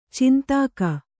♪ cintāka